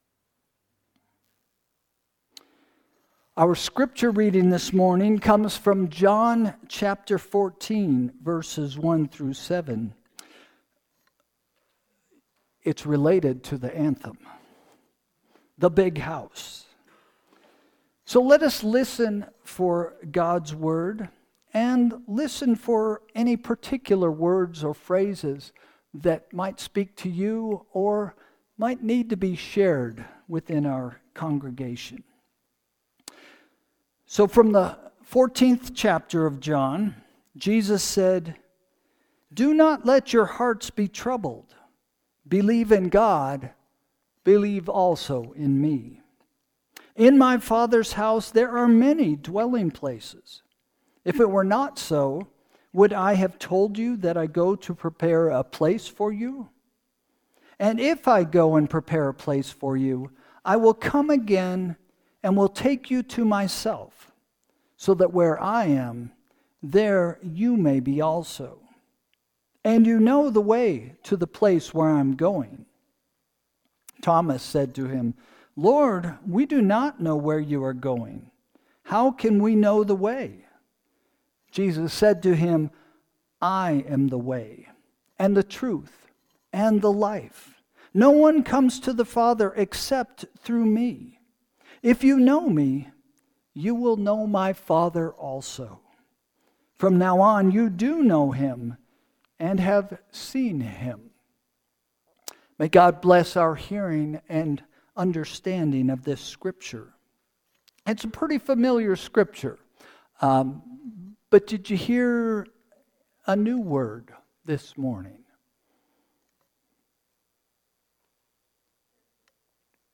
Sermon – January 12, 2025 – “Big House” – First Christian Church